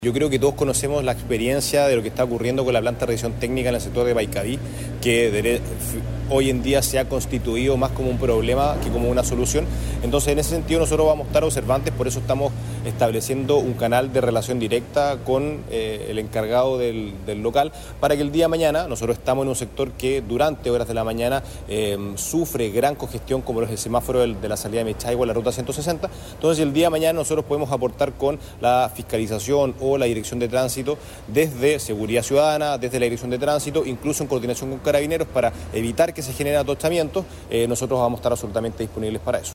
Tras el recorrido, la máxima autoridad comunal subrayó la importancia de esta nueva infraestructura en el plan de descentralización de servicios de la comuna.